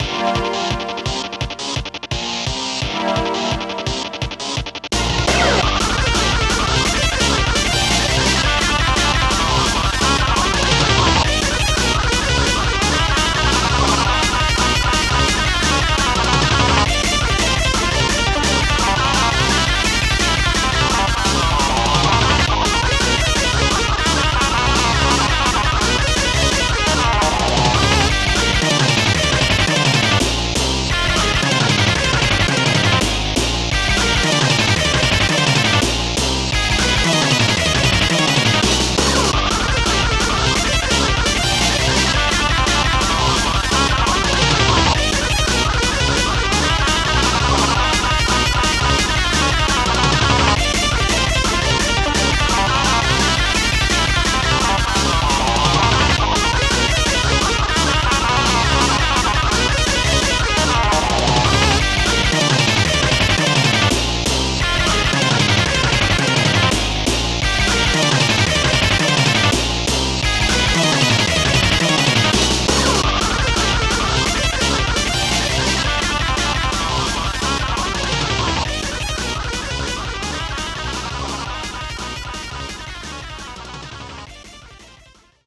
BPM171
Audio QualityMusic Cut
File is read: SNES GUITAR RUN.